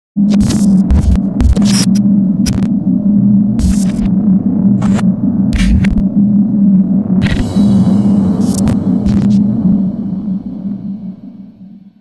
future-glitch-sound-effect.mp3